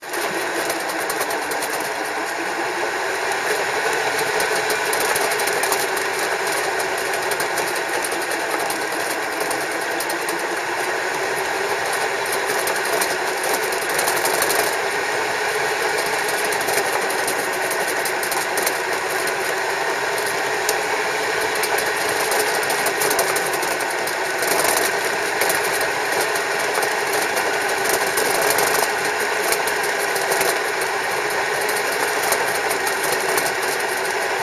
• two worrisome noises–transmission/prop shaft rotation
Anyway, on the way down from san francisco I listened to our transmission making a clacking noise which may or may not be completely normal.
The audio file I am attaching to this post was recorded while sailing (motor was not running), and the gear shift is in neutral.
You will hear the whirring of the prop shaft spinning, and then you will hear a clattering/clacking noise that I can pinpoint to be coming from the transmission (not the engine or v-drive).